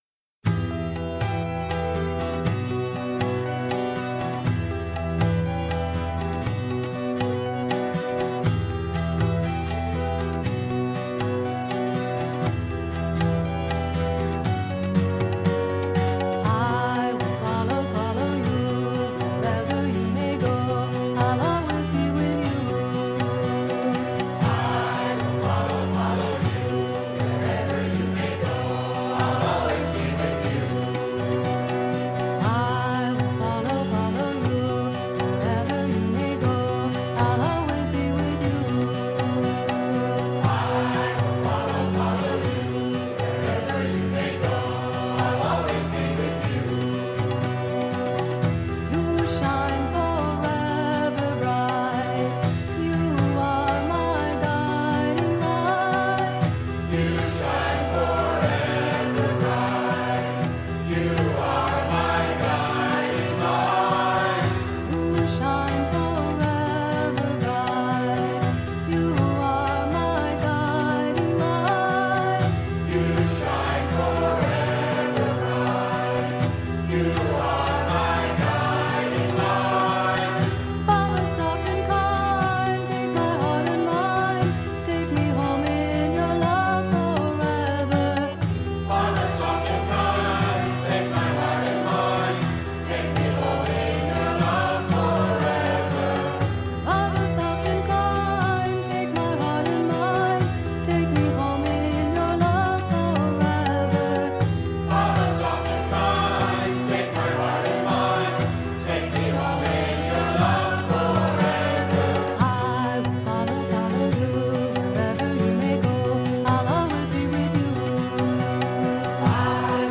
1. Devotional Songs
Minor (Natabhairavi)
8 Beat / Keherwa / Adi
4 Pancham / F
1 Pancham / C